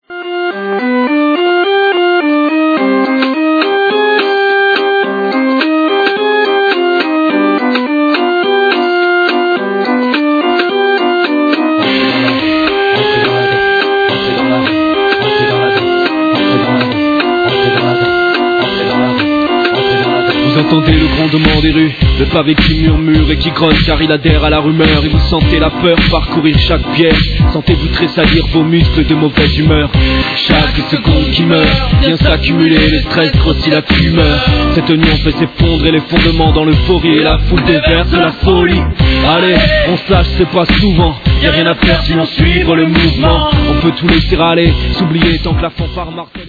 l'électrochansonnette